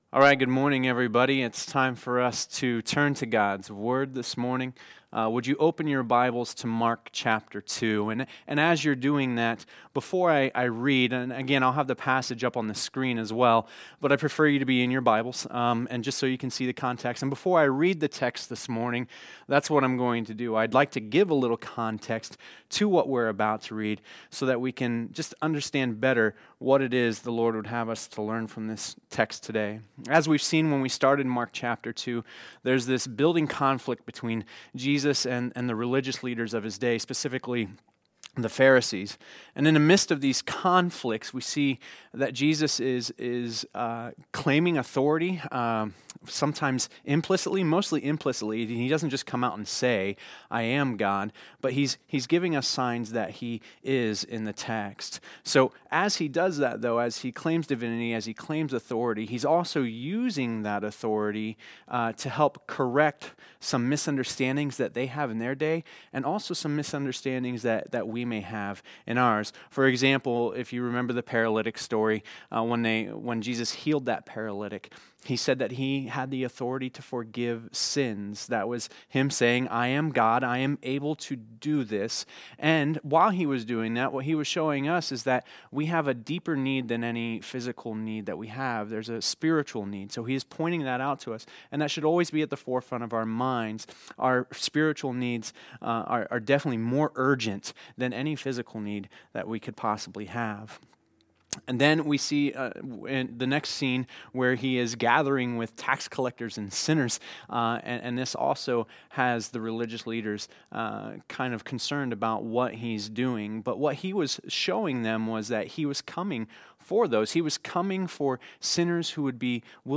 There were some recording issues during the first portion of this message. It picks up a few minutes into the sermon.